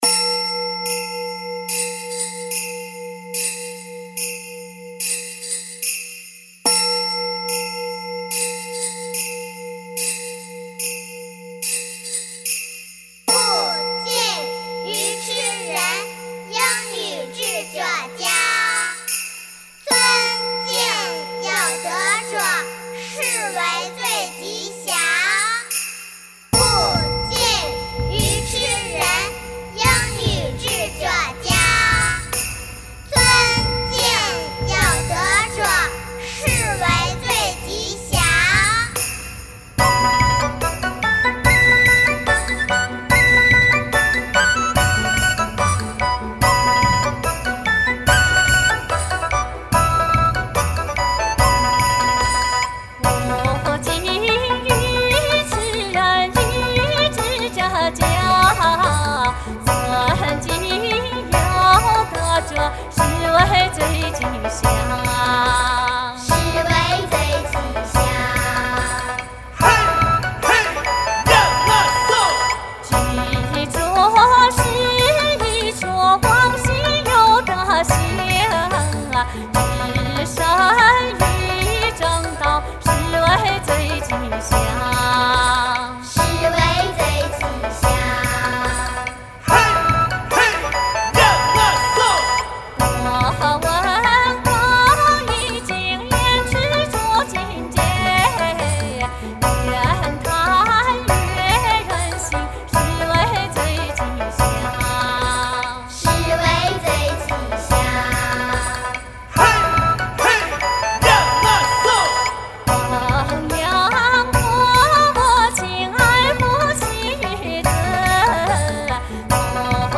民歌改编